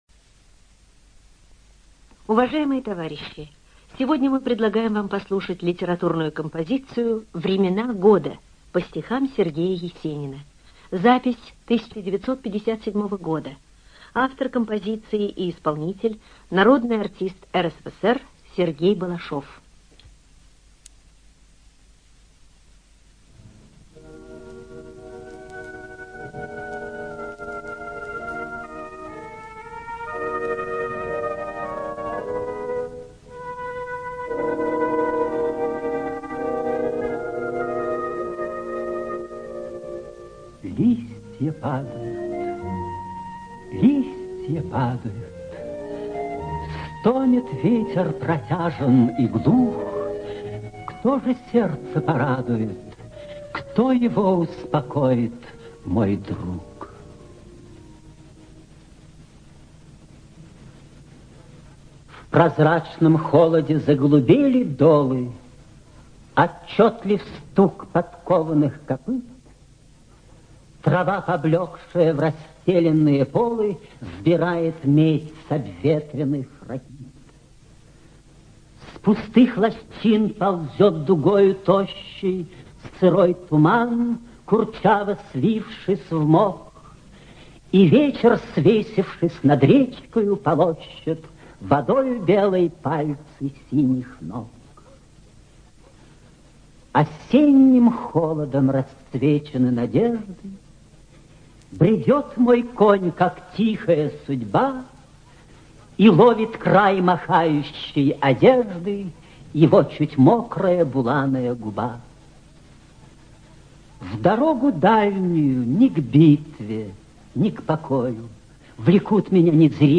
ЖанрПоэзия